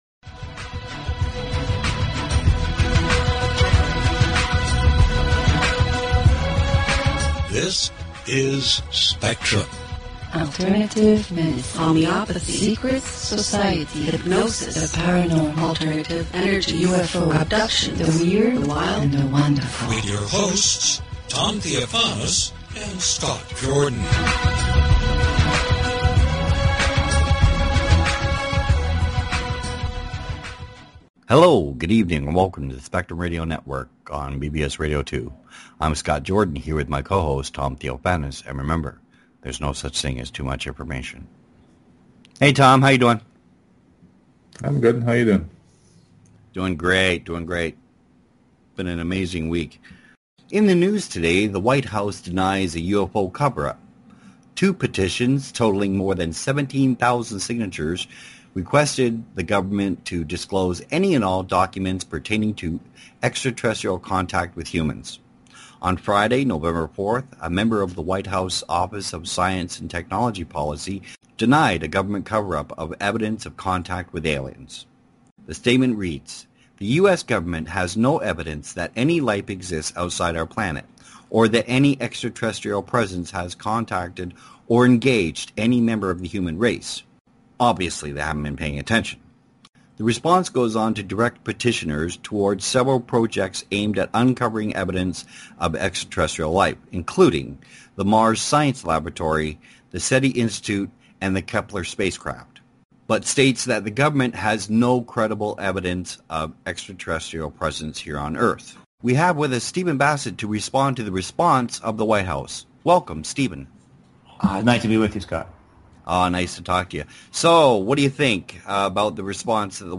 Talk Show Episode, Audio Podcast, Spectrum_Radio_Network and Courtesy of BBS Radio on , show guests , about , categorized as